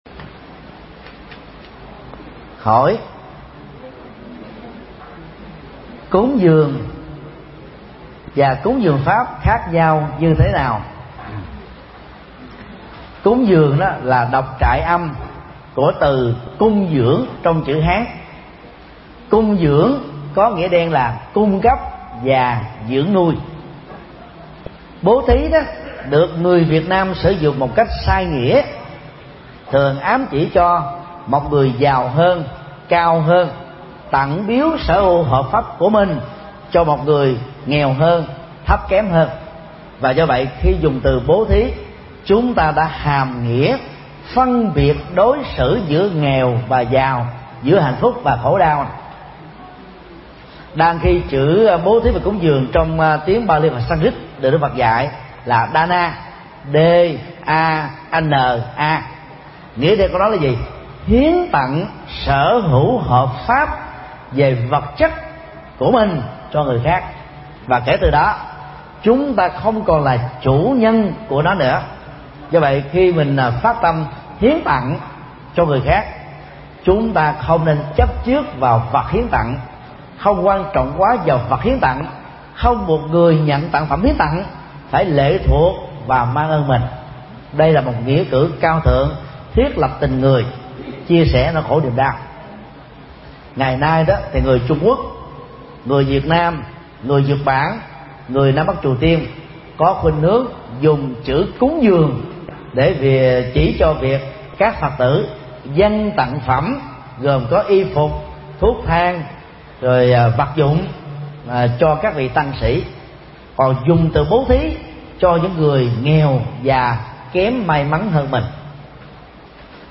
Vấn đáp